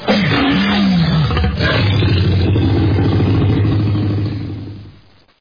Download Tyrannosaurus Rex sound effect for free.
Tyrannosaurus Rex